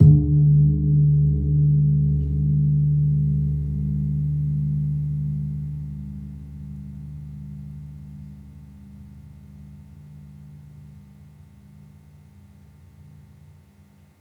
Gong-D1-f.wav